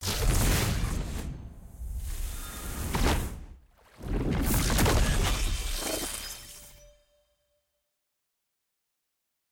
sfx-exalted-rolling-ceremony-single-anim.ogg